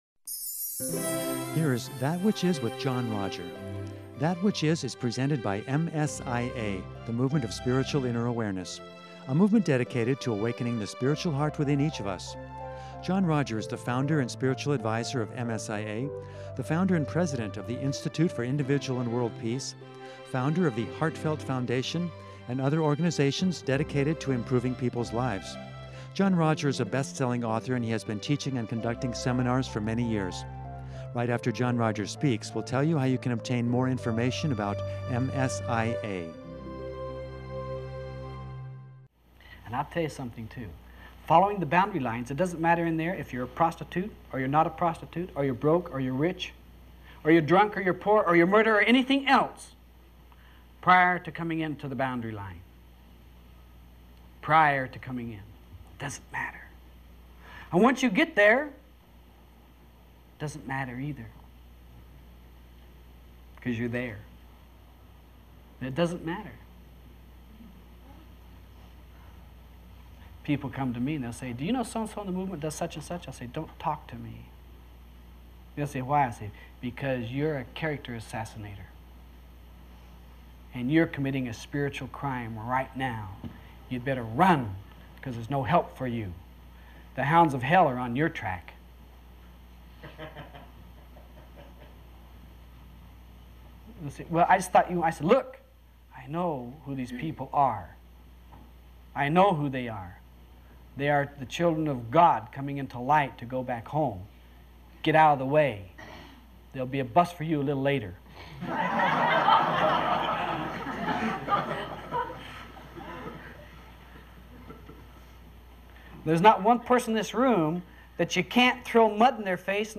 In part two of this seminar